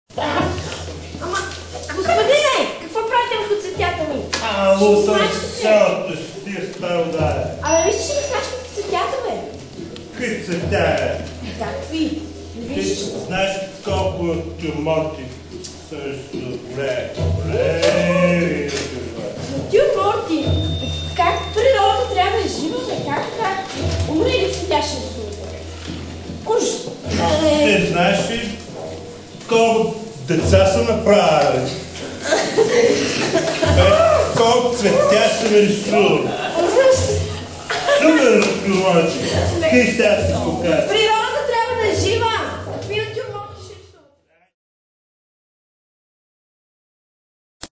Cello & Elektronik
Tabla & Perc
Den musikalischen Rahmen werden spartanische Kammermusik und zeitgenössischer, weltmusikalischer Jazz unter Einbeziehung spezifischer Elemente der bulgarischen Folklore bilden.